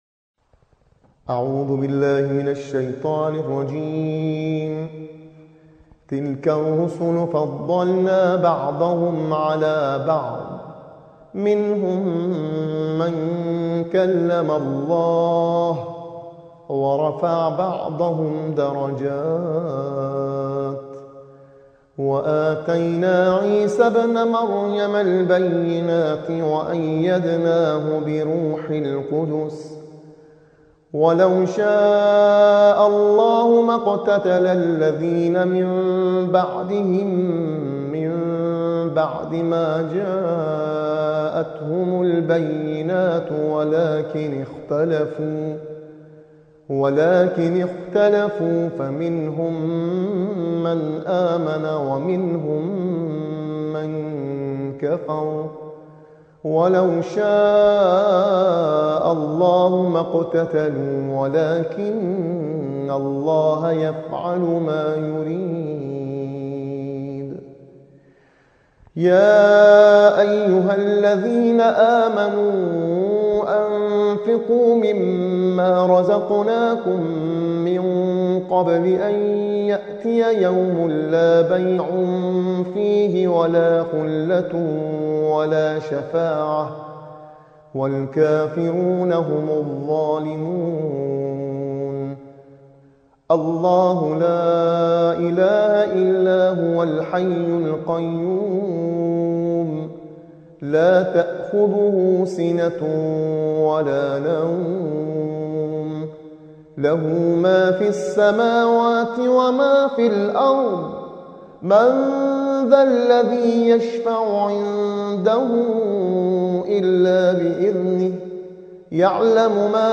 صوت | ترتیل‌خوانی